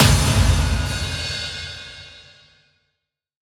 Big Drum Hit 21.wav